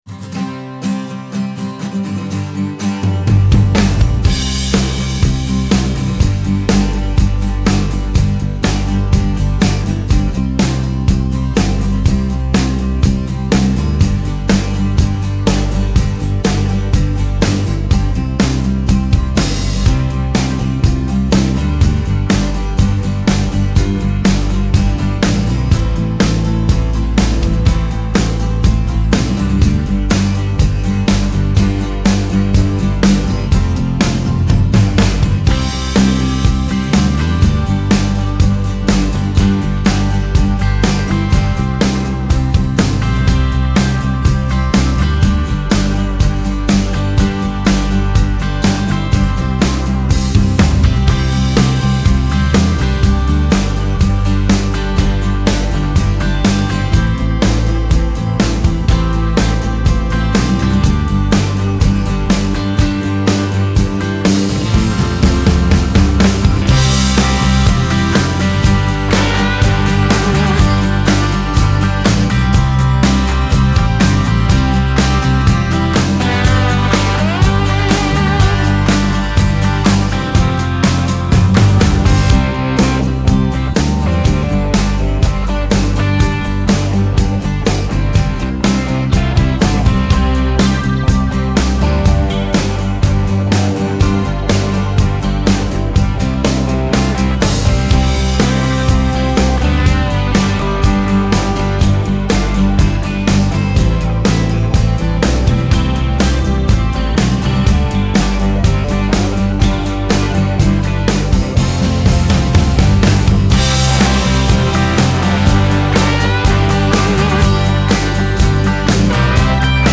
Genre : Alternative & Indie